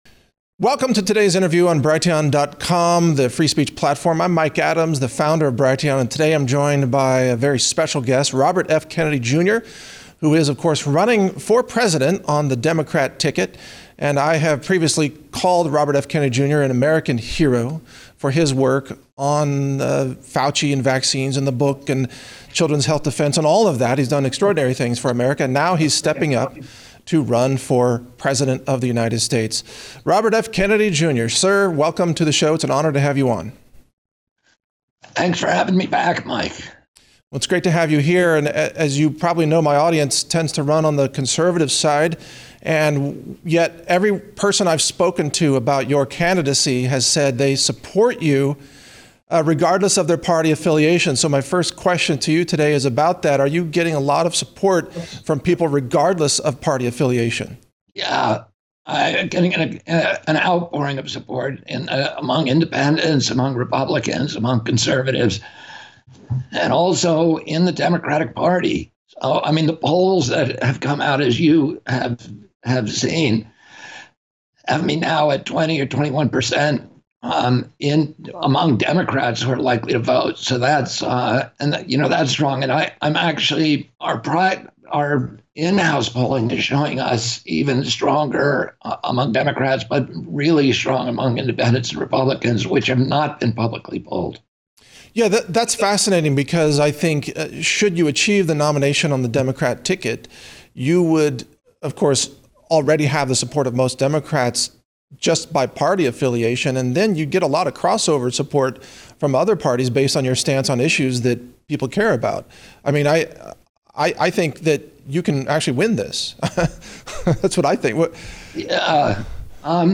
Robert F. Kennedy, Jr. interviewed by Mike Adams: Election integrity, free speech, secure borders and more